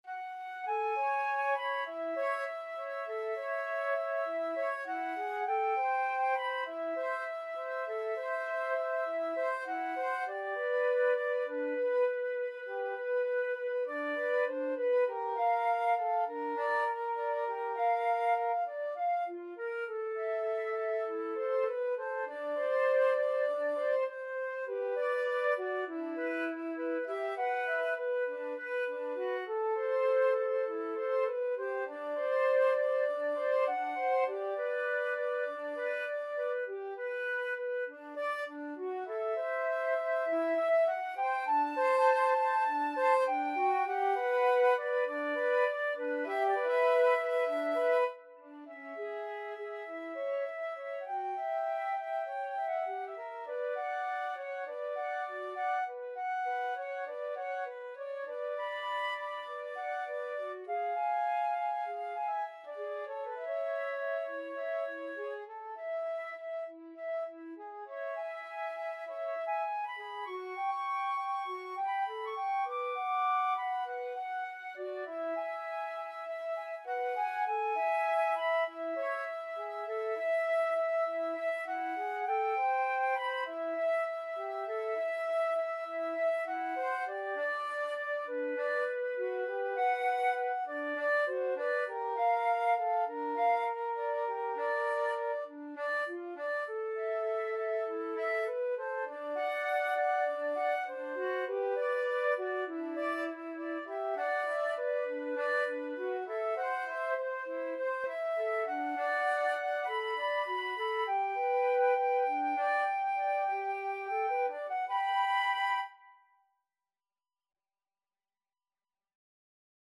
Flute 1Flute 2
A sultry piece in the rhythm known as 'Beguine'.
4/4 (View more 4/4 Music)